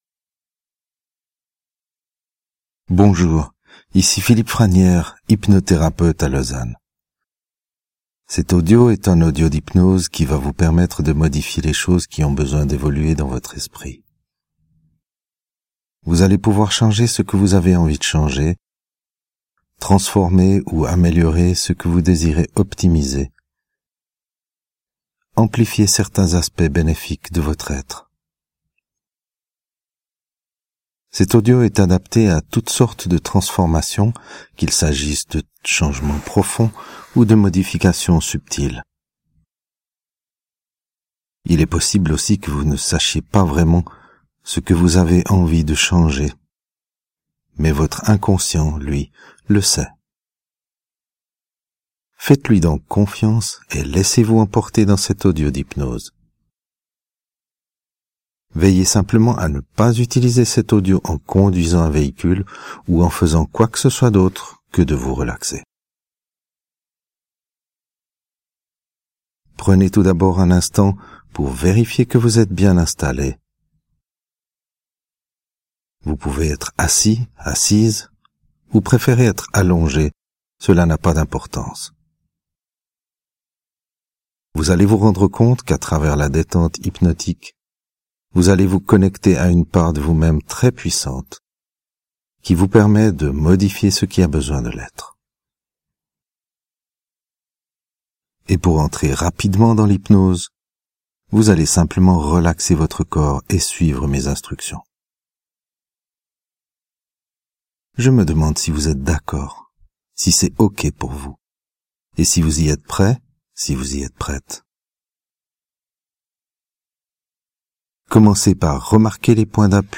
Je vous souhaite beaucoup de plaisir dans cette écoute hypnotique.